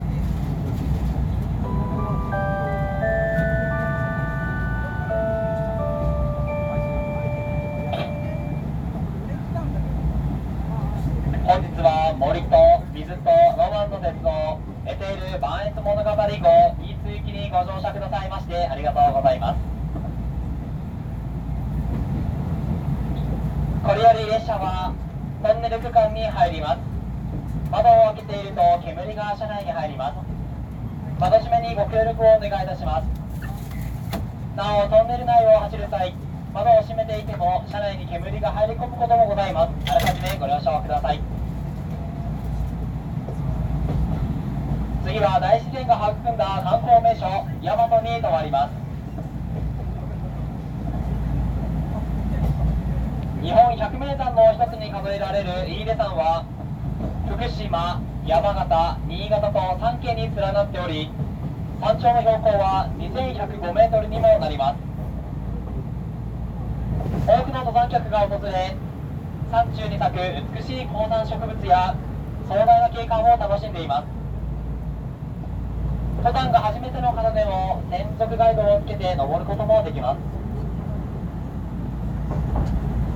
〜車両の音〜
・ばんえつ物語号 車内放送
喜多方発車 次は山都
（♪ハイケンスのセレナーデ）